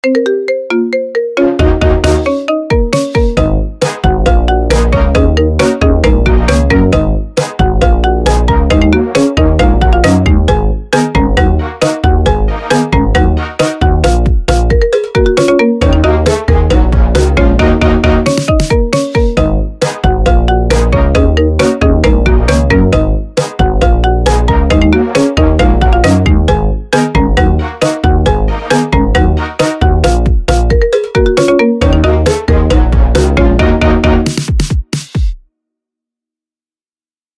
Catégorie Marimba Remix